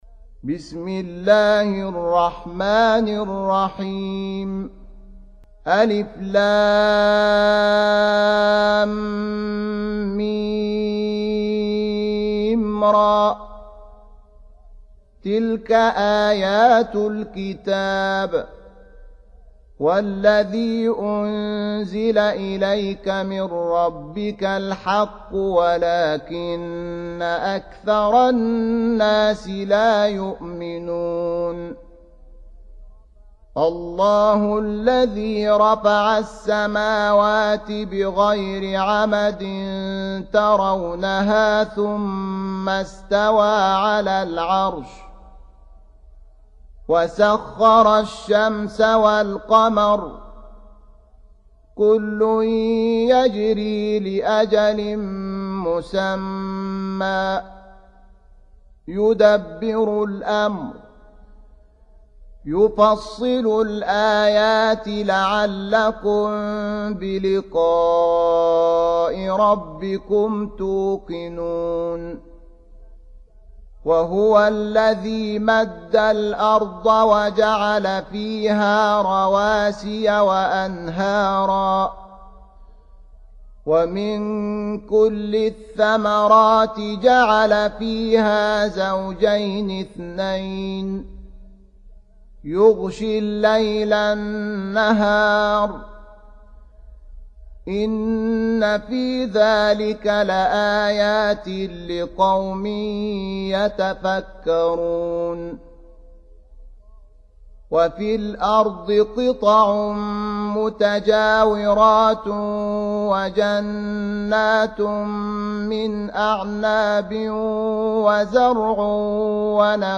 13. Surah Ar-Ra'd سورة الرعد Audio Quran Tarteel Recitation
Surah Repeating تكرار السورة Download Surah حمّل السورة Reciting Murattalah Audio for 13. Surah Ar-Ra'd سورة الرعد N.B *Surah Includes Al-Basmalah Reciters Sequents تتابع التلاوات Reciters Repeats تكرار التلاوات